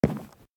wood3.ogg